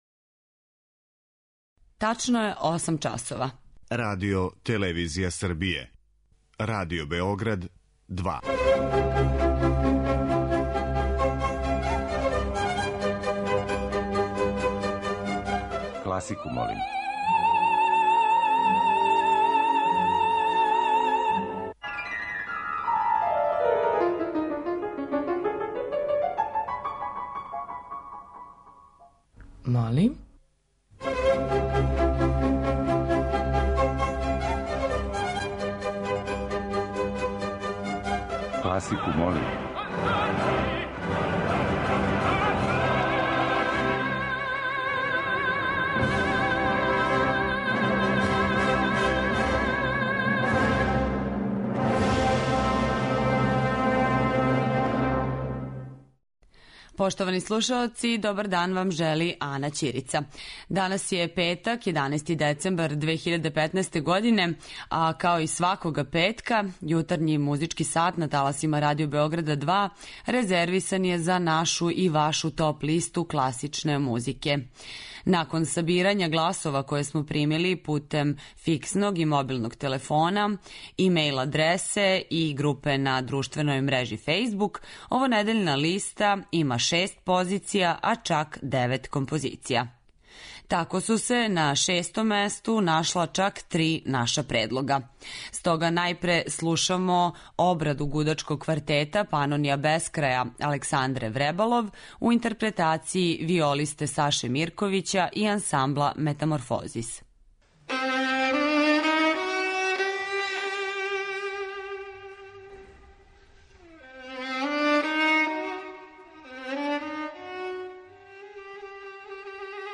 Недељна топ-листa класичне музике Радио Београдa 2